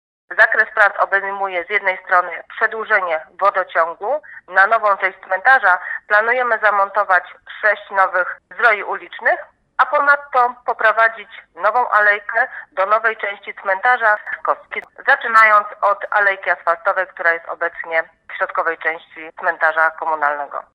Na Cmentarzu Komunalnym w Stalowej Woli powstaną nowe zdroje uliczne. To część zadania które obejmie nową część cmentarza. Mówi wiceprezydent Stalowej Woli Renata Knap: